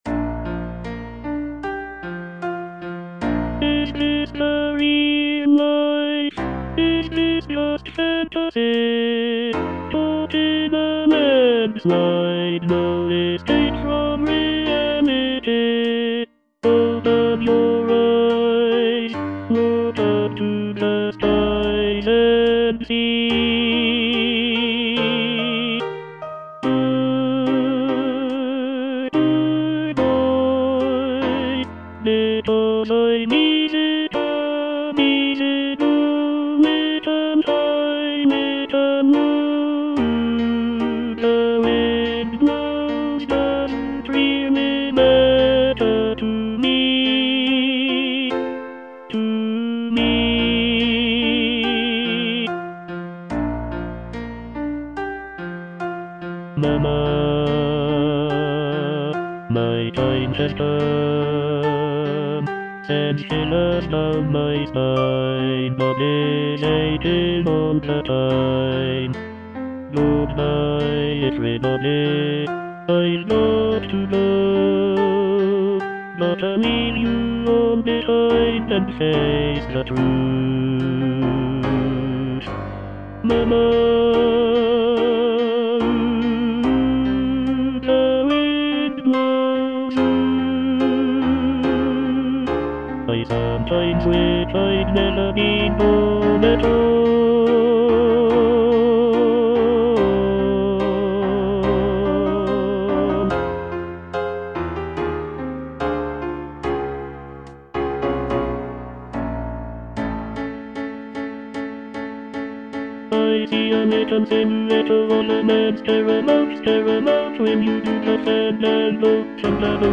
Tenor II (Voice with metronome)